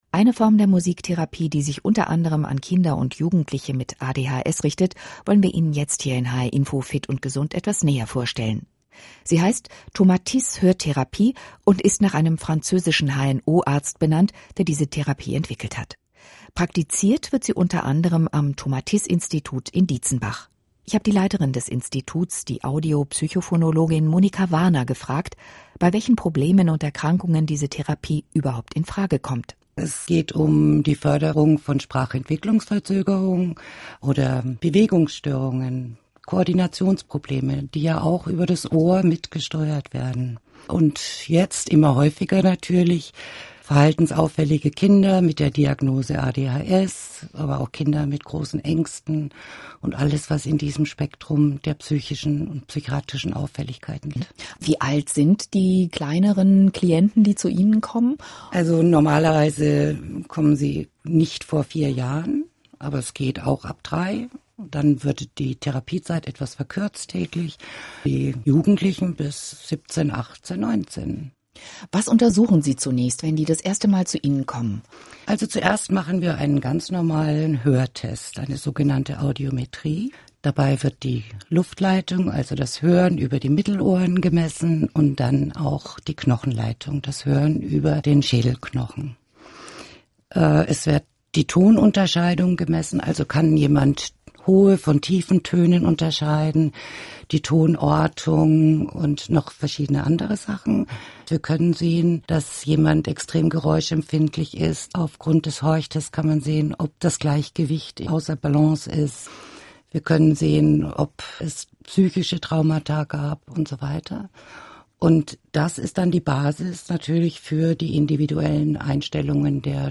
hr-iNFO, Radiobeitrag zur Hörtherapie